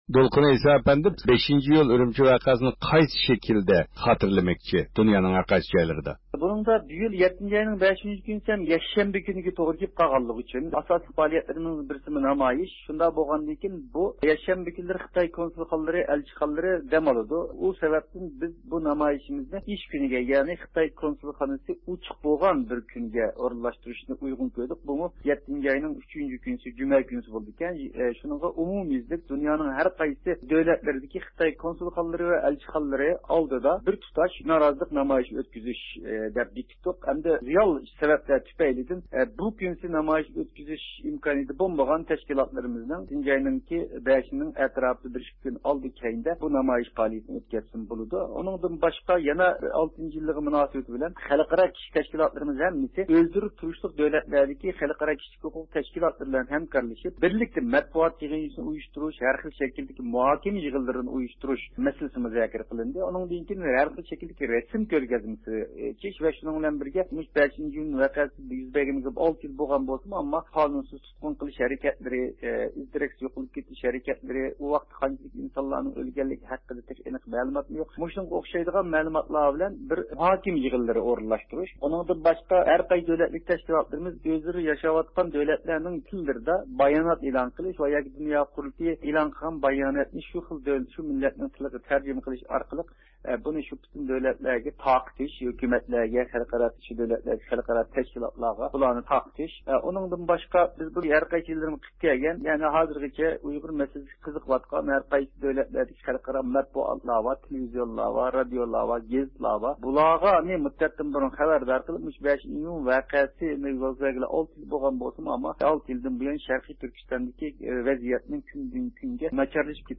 بىز بۇ يىغىن توغرىسىدا تېخىمۇ تەپسىلىي مەلۇمات ئىگىلەش ئۈچۈن دۇنيا ئۇيغۇر قۇرۇلتېيى ئىجرائىيە كومىتېتى مۇدىرى دولقۇن ئەيسا ئەپەندى سۆھبەت ئېلىپ باردۇق.